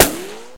ricochet sounds